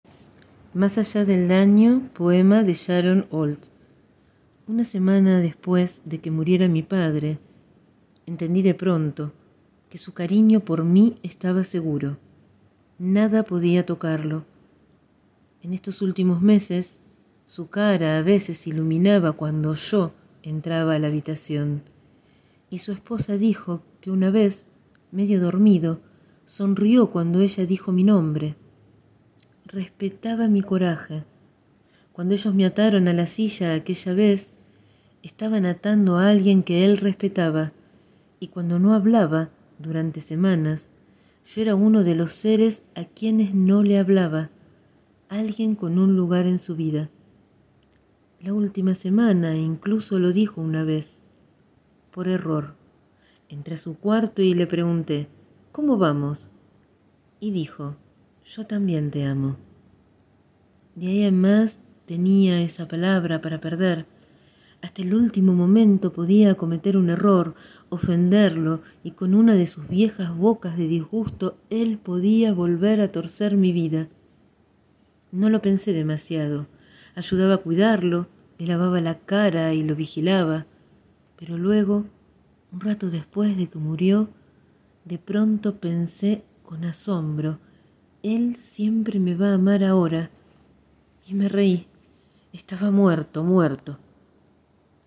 Hoy dejo en audio mi voz leyendo el poema «Más allá del daño» de Sharon Olds, poeta nacida en 1942 (California).